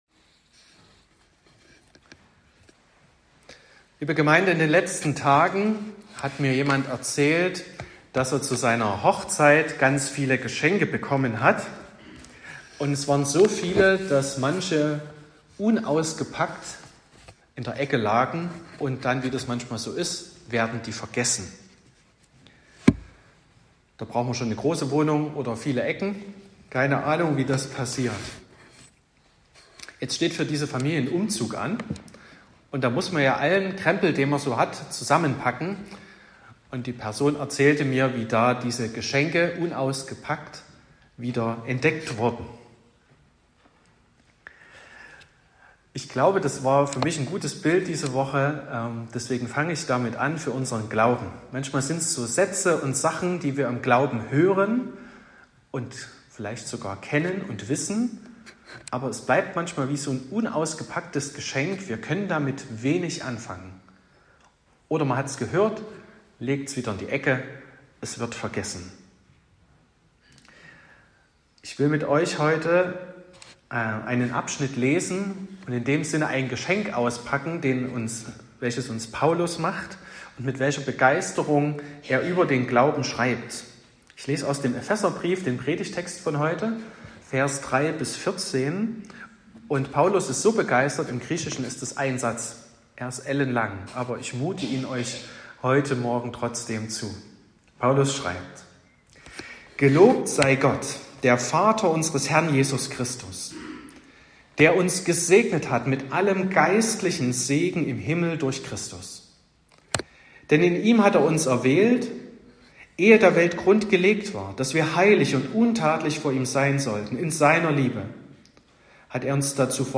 26.05.2024 – Gottesdienst
Predigt und Aufzeichnungen